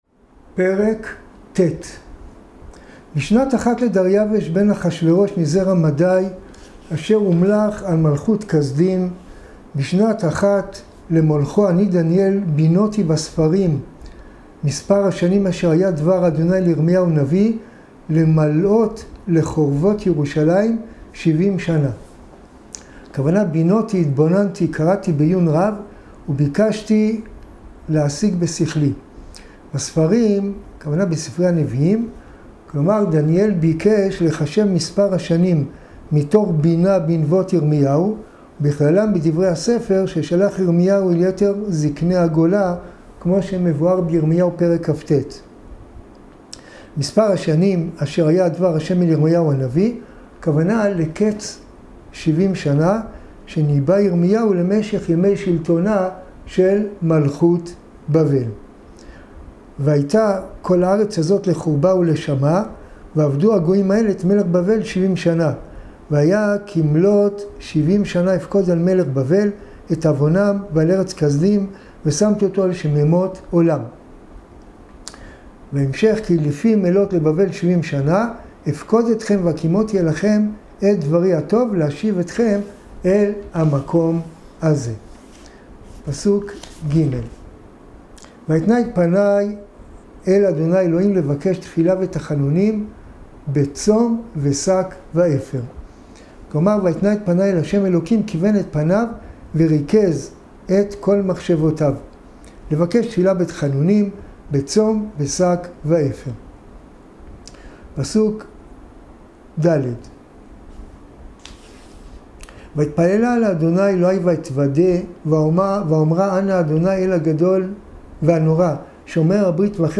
שיעורים בתנ"ך - ספר דניאל ספר דניאל פרק א גלות יהויקים.